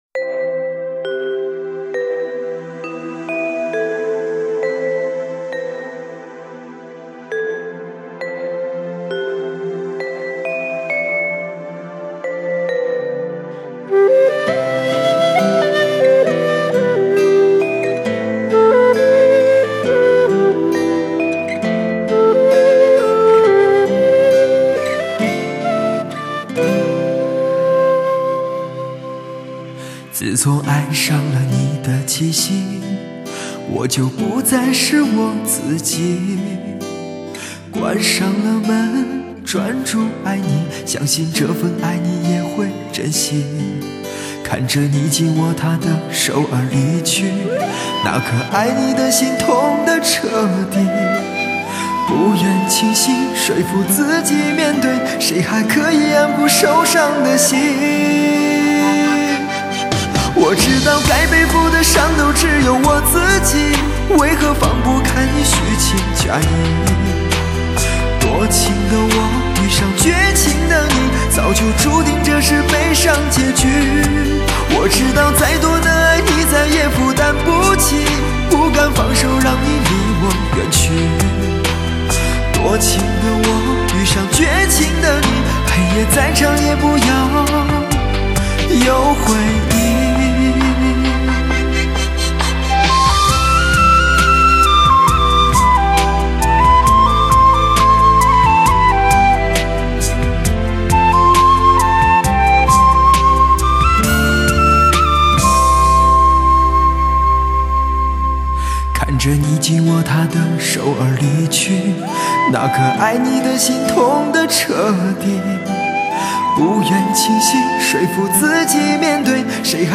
音场宽广 音质纯正 缔造完美发烧天碟 静静聆听和品味最深情最优雅的魅惑男声